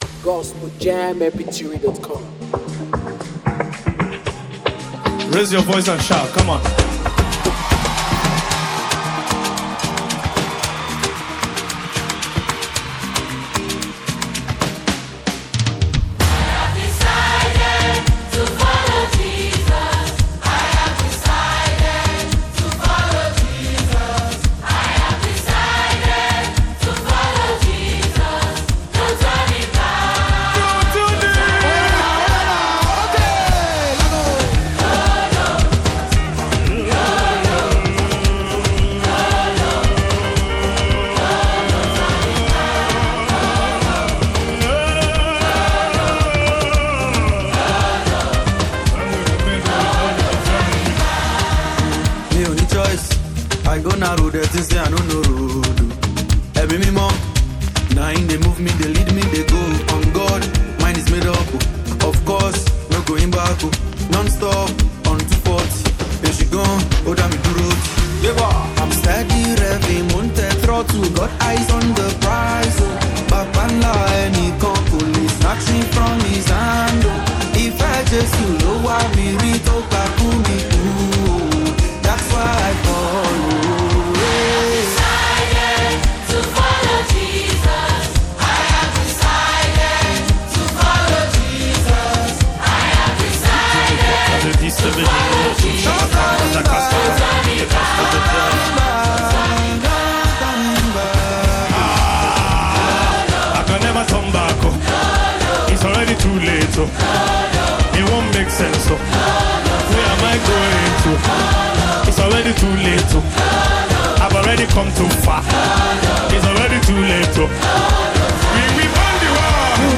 afro Gospel singer
remix